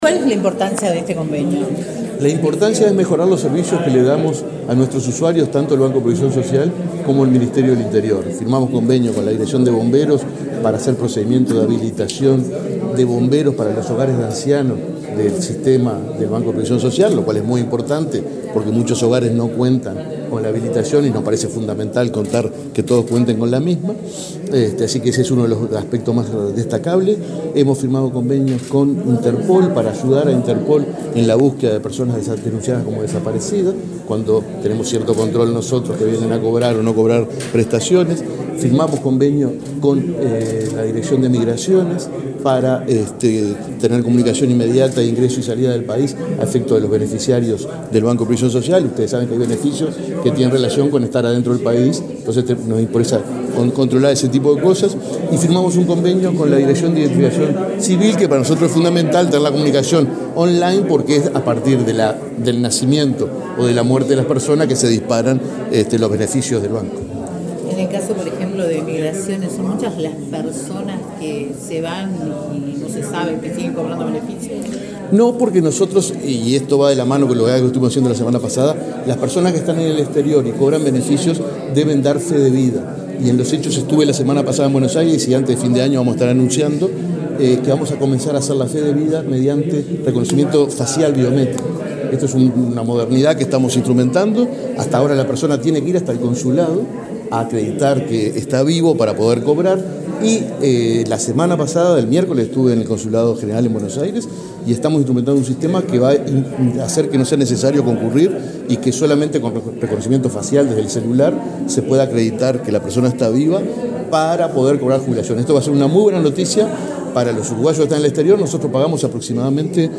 Declaraciones del presidente del BPS, Alfredo Cabrera
Declaraciones del presidente del BPS, Alfredo Cabrera 07/12/2022 Compartir Facebook X Copiar enlace WhatsApp LinkedIn El Banco de Previsión Social (BPS) firmó un convenio marco con el Ministerio del Interior y acuerdos específicos con la Dirección Nacional de Identificación Civil, la Dirección General de Lucha contra el Crimen Organizado e Interpol y la Dirección Nacional de Migración. Luego el presidente del BPS, Alfredo Cabrera, dialogó con la prensa.